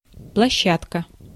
Ääntäminen
Ääntäminen US
IPA : /ˈpleɪˌgraʊnd/